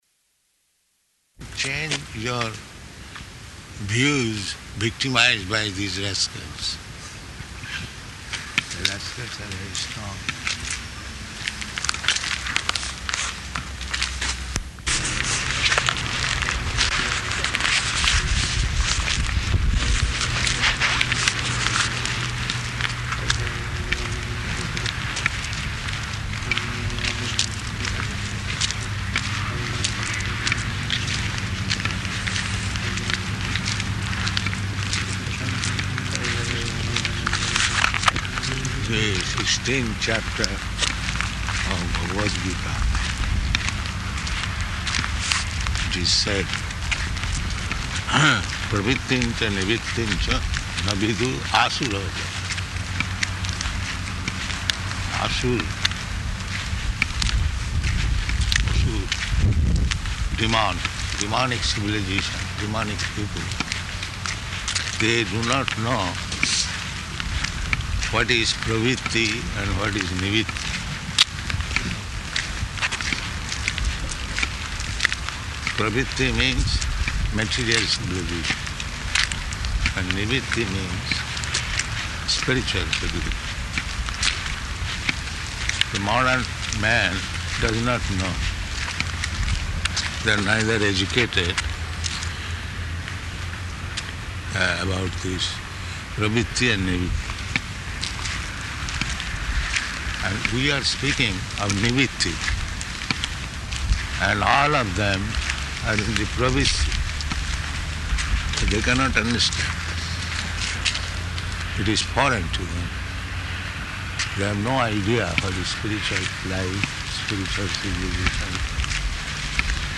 Morning Walk --:-- --:-- Type: Walk Dated: May 13th 1975 Location: Perth Audio file: 750513MW.PER.mp3 Prabhupāda: ...change your views victimized by these rascals.